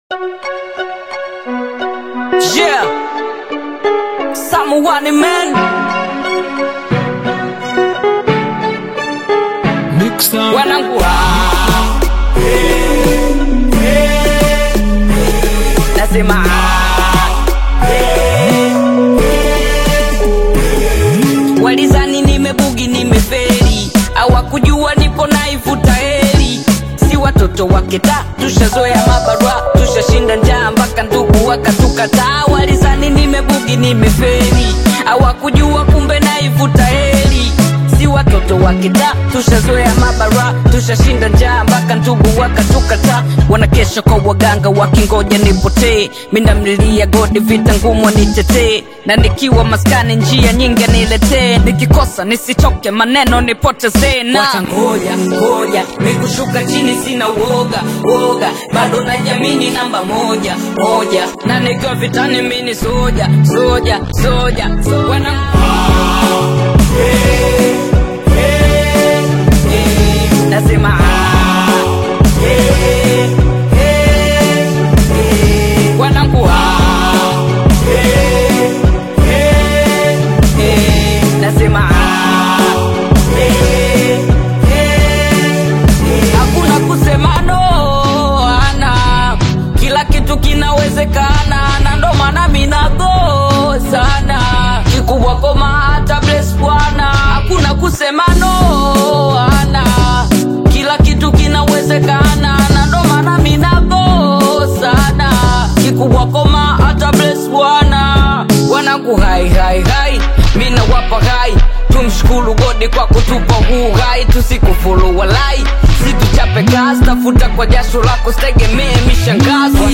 an upbeat Afro-Pop/Bongo Flava single
With its feel-good vibes and memorable hooks